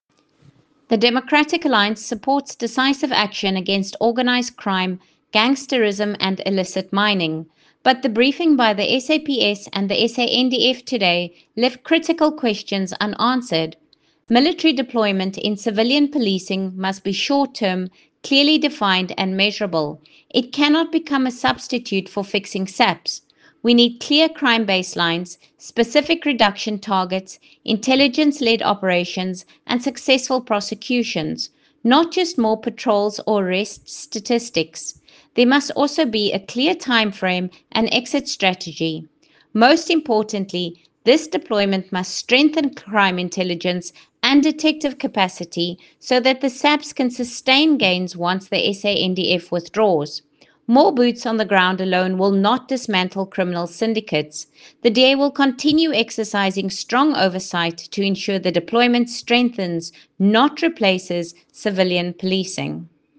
Afrikaans soundbites by Lisa Schickerling MP.